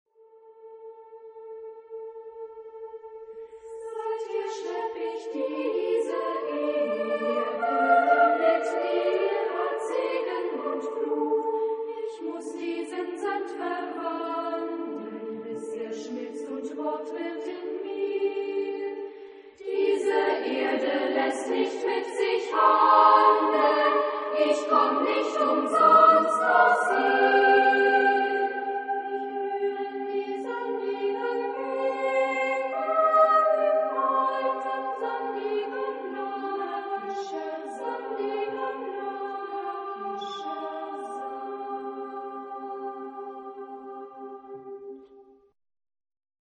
Genre-Style-Forme : Cycle ; Pièce chorale ; Profane
Type de choeur : SSAA  (4 voix égales de femmes )
Tonalité : atonal